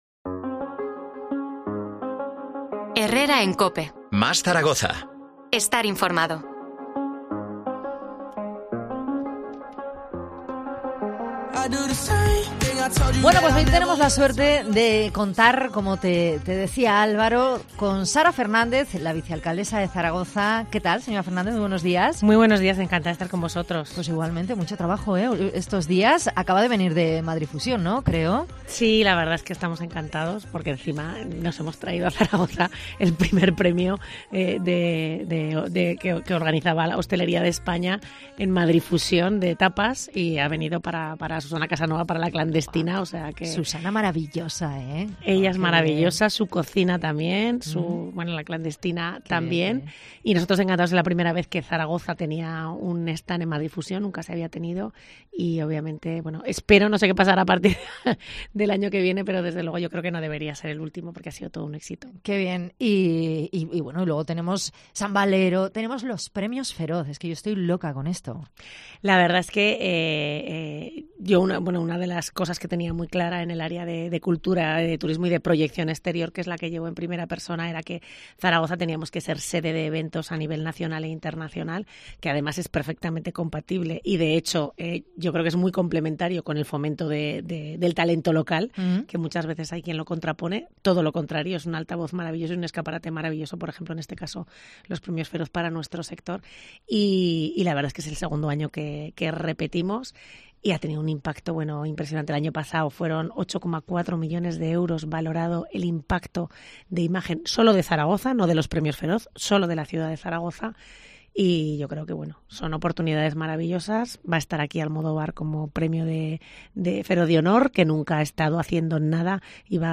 Entrevista a Sara Fernández, vicealcaldesa de Zaragoza